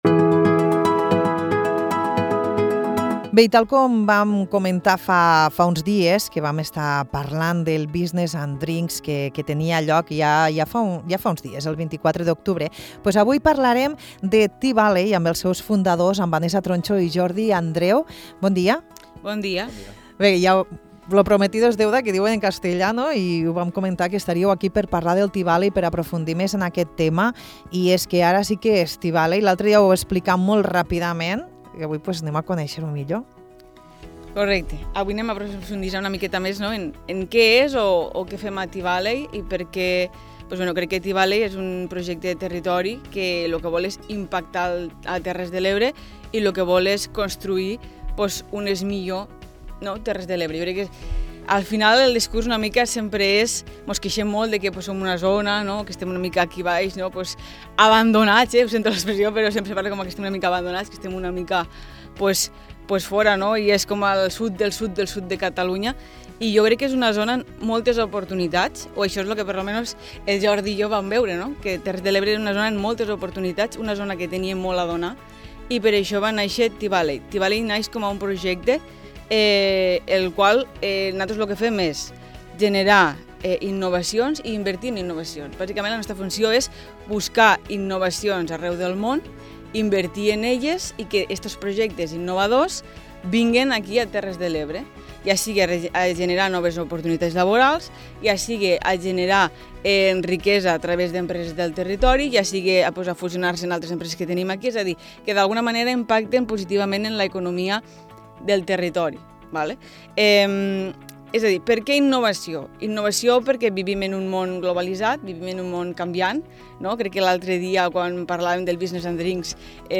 En l’entrevista, ens expliquen què és T-Valley, com va néixer i les iniciatives que estan duent a terme per promoure la innovació i el desenvolupament. Descobrim com T-Valley busca potenciar l’emprenedoria local, així com les oportunitats que ofereix tant a empreses com a ciutadans per crear un futur més dinàmic.